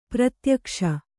♪ pratyakṣa